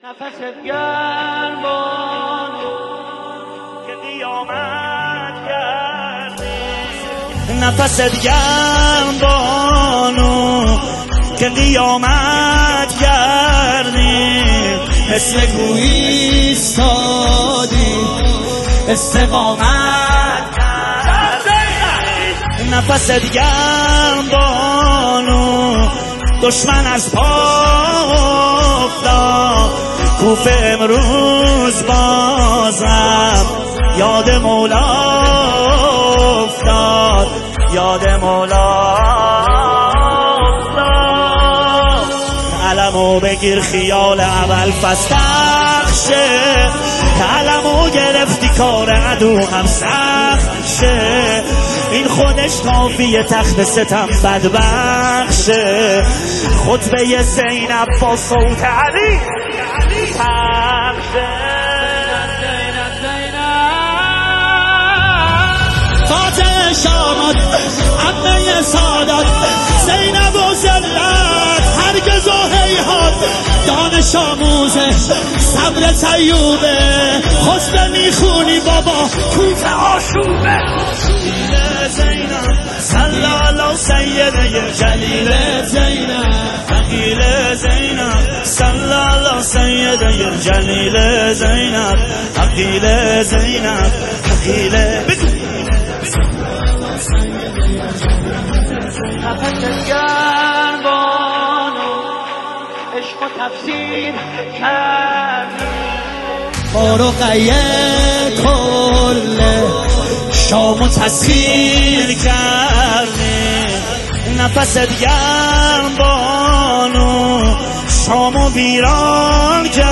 به مناسبت وفات حضرت زینب سلات الله علیها